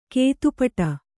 ♪ kētu paṭa